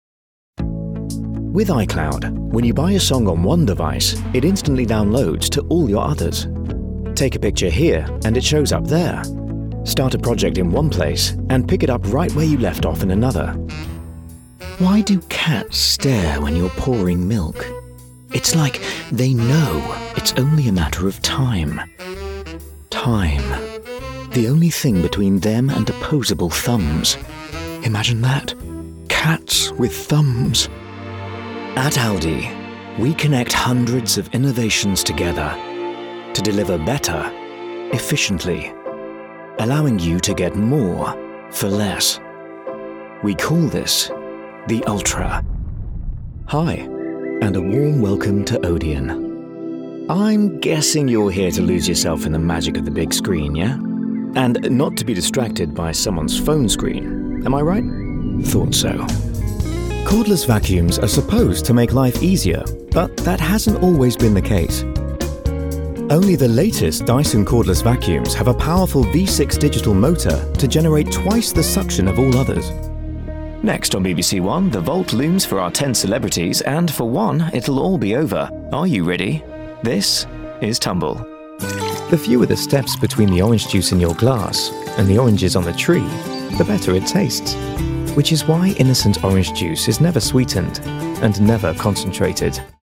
Commercial Reel.mp3